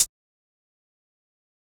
Closed Hats
Hihat (Too Late).wav